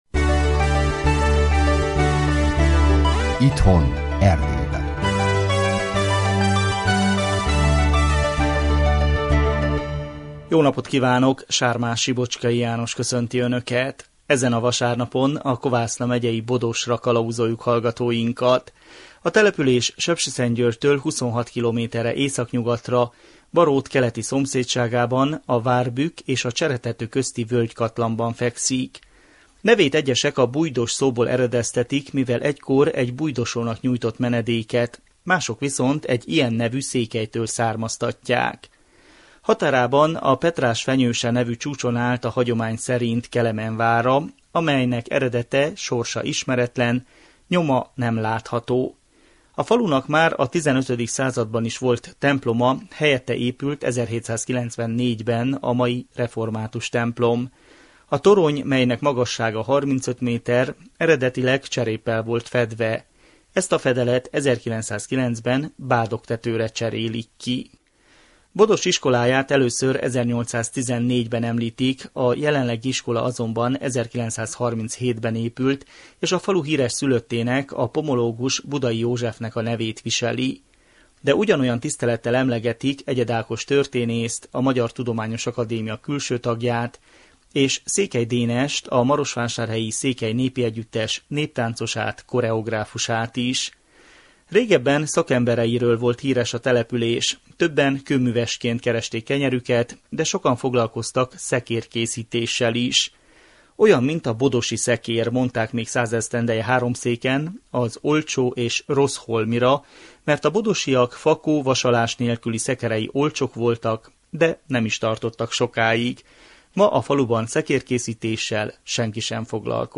Műsorunk a 2016 február 28-i műsor ismétlése!